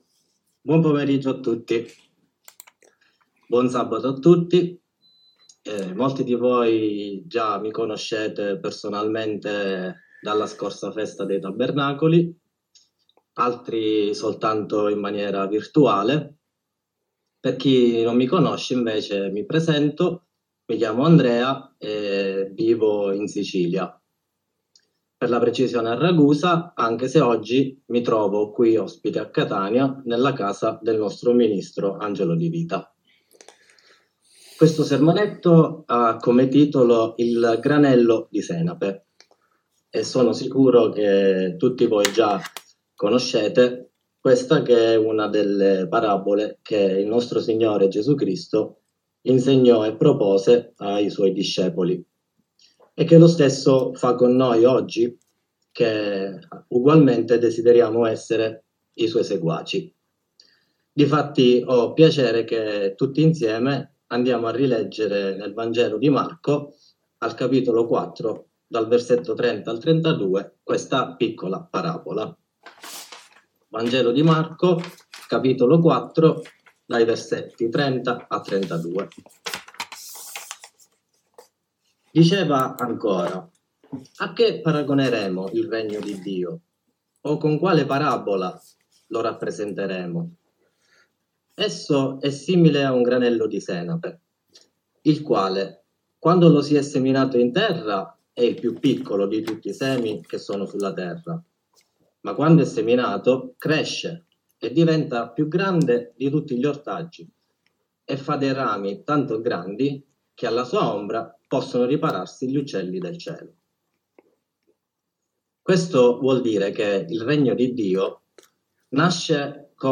Sermonetto